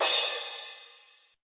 撞车
描述：电子音乐崩溃。
标签： 碰撞
声道立体声